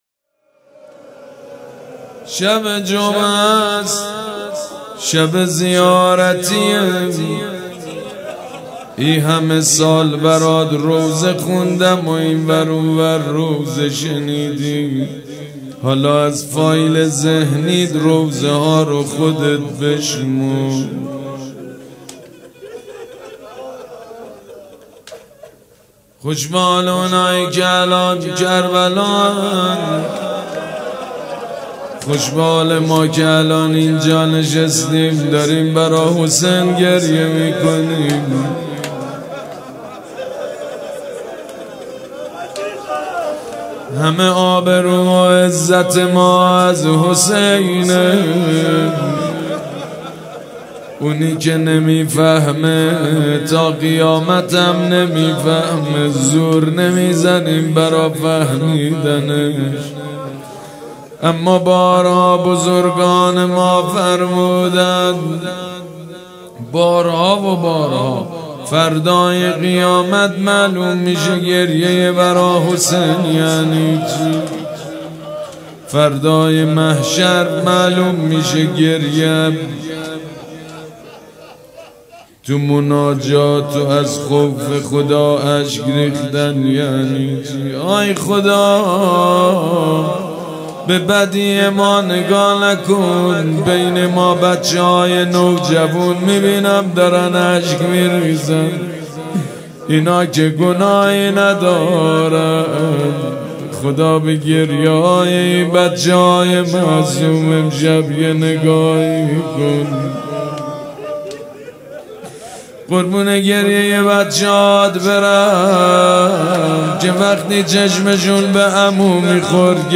مراسم مناجات شب بیست و چهارم ماه رمضان
روضه